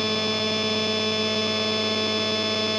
Шумы и НЁХ
23:27:46 » Объясните, может ли это непонятное явление за моим окном создавать такие помехи? noise.wav